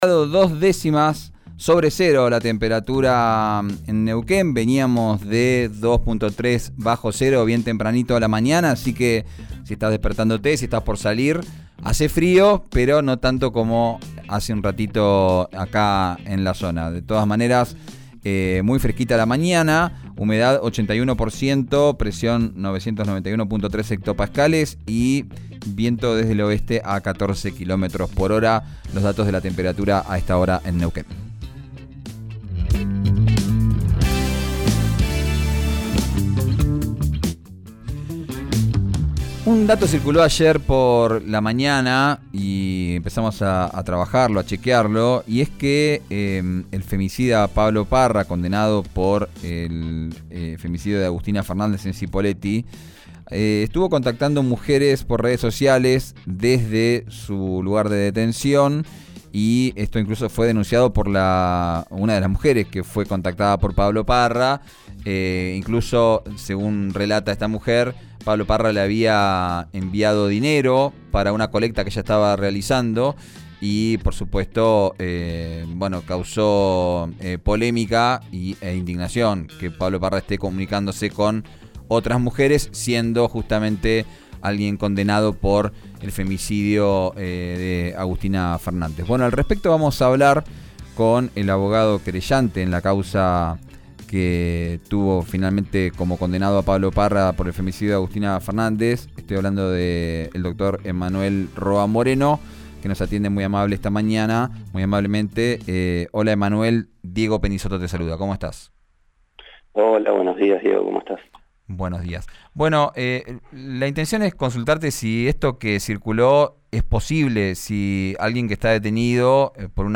abogado querellante, en RÍO NEGRO RADIO